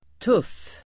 Uttal: [tuf:]